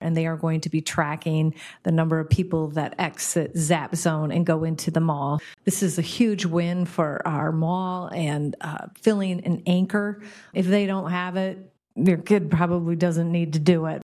Mayor Randall says they will hold the ribbon cutting at the new facility at 11:30 this morning.